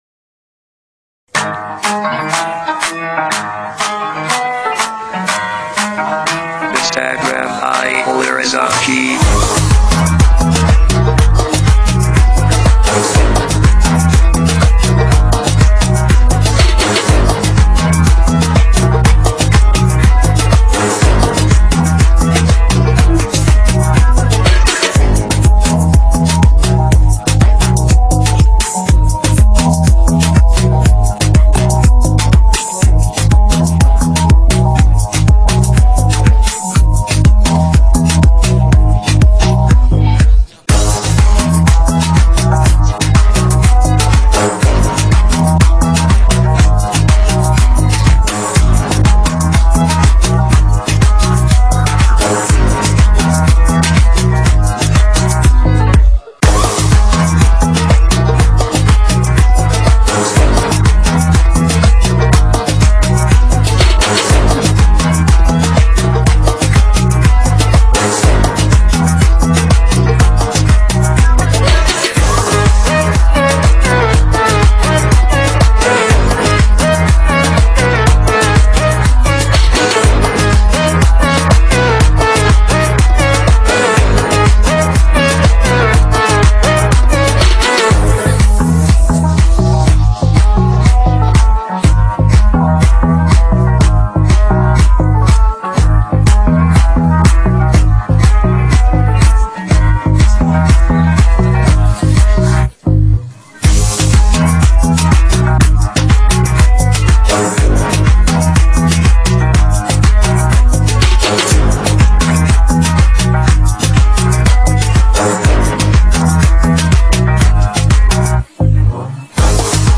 بی کلام بدون صدای خواننده